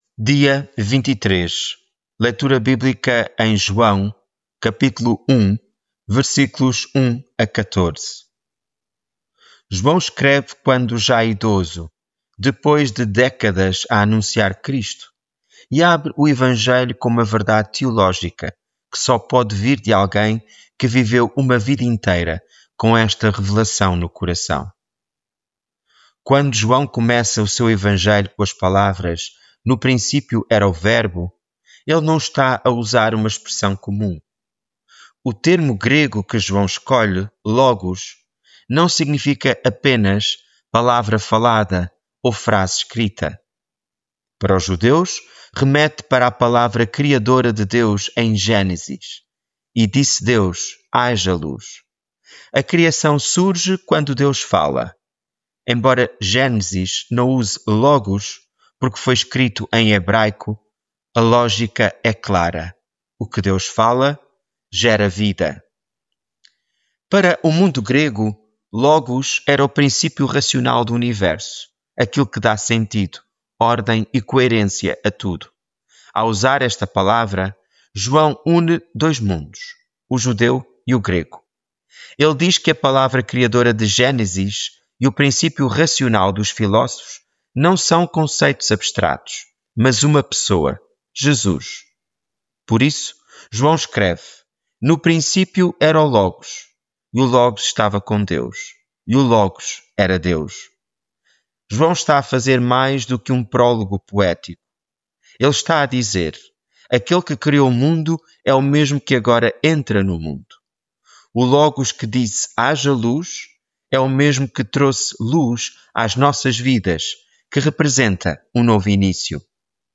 Devocional
Leitura bíblica em João 1:1-14